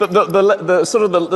Elon Stutter Sound